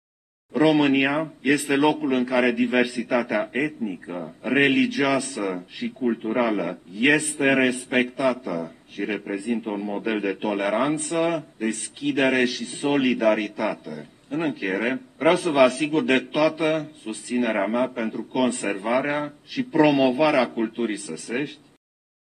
Preşedintele Klaus Iohannis a participat, în localitatea braşoveană Criţ, la evenimentele prilejuite de festivalul dedicat tradițiilor și culturii săsești din Țara Ovăzului, respectiv zona cuprinsă între Rupea și Sighișoara.
Șeful statului a precizat că astfel de evenimente nu sunt simple acte artistice, ci constituie premisele revitalizării zonei: